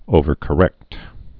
(ōvər-kə-rĕkt)